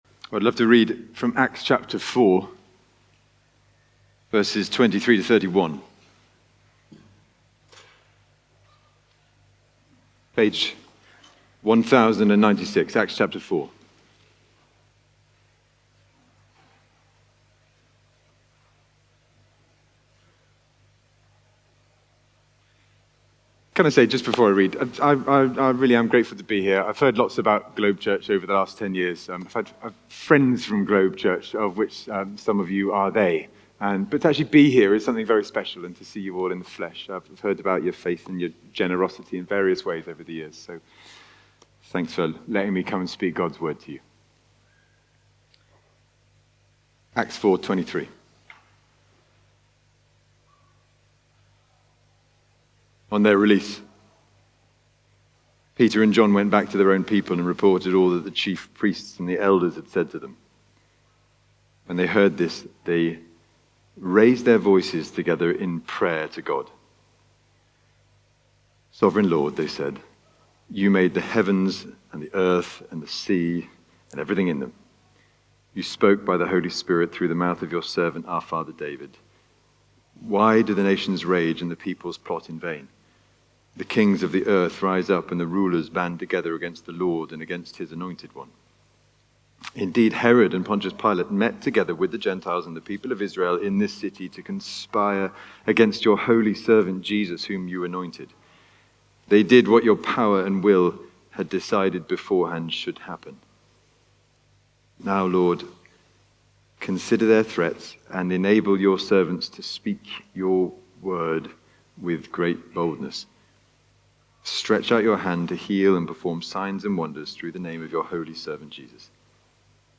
Sermon-Jan-4-1.mp3